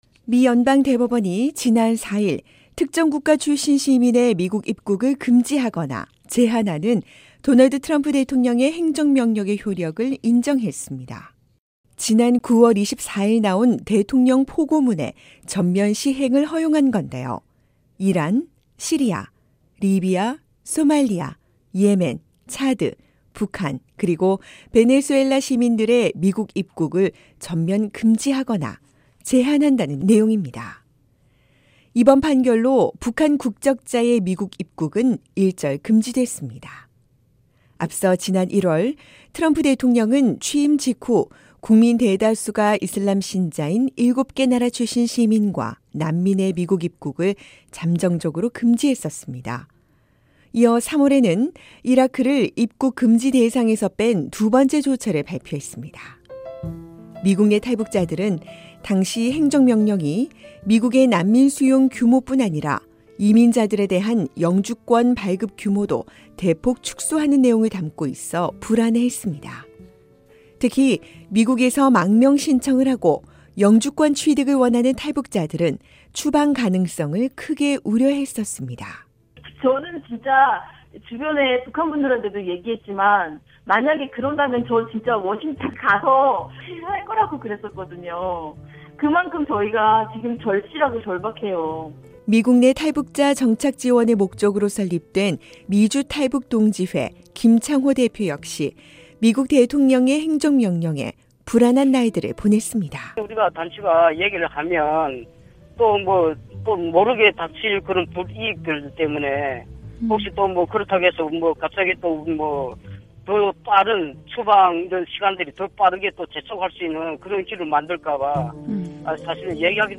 미국 연방 대법원이 북한 등 8개 나라 국적자의 미국 입국을 제한하는 도널드 트럼프 대통령의 행정명령 시행을 허용했습니다. 미국 내 탈북자들의 반응을 들어봤습니다.